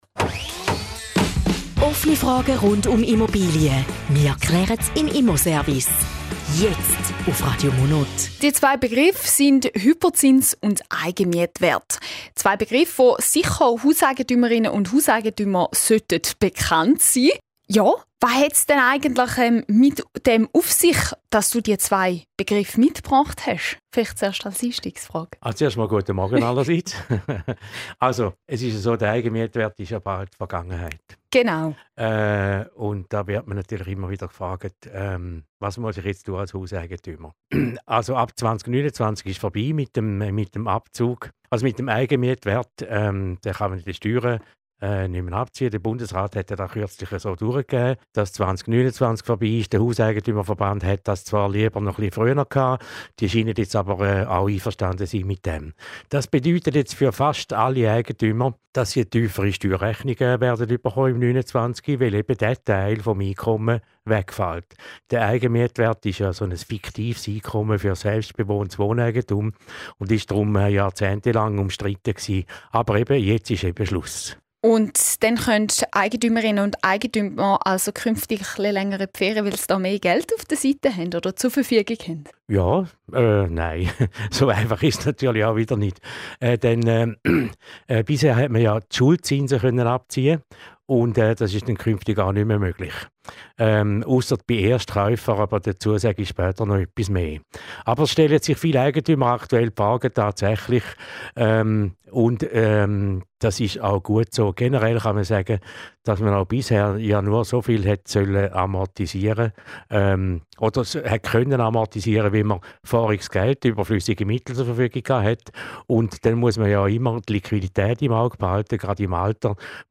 Zusammenfassung des Interviews zum Thema „Hypozinsen und Eigenmietwert“